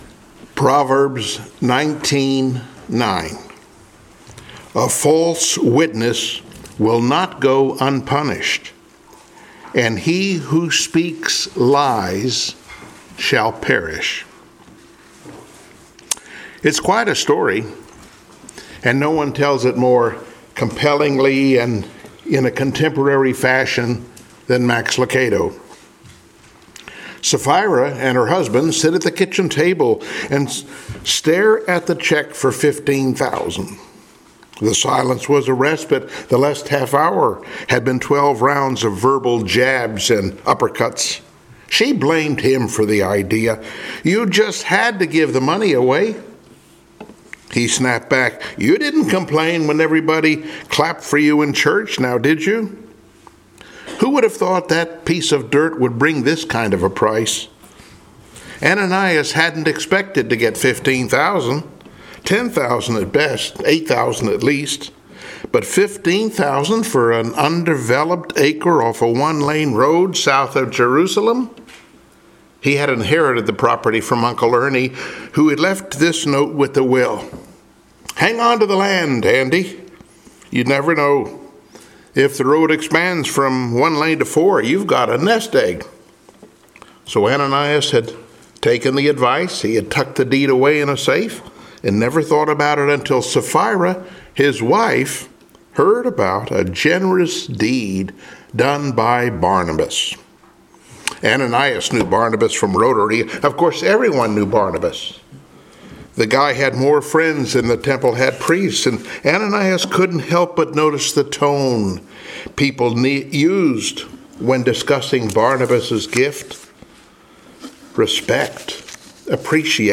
Passage: Acts 5:1-11 Service Type: Sunday Morning Worship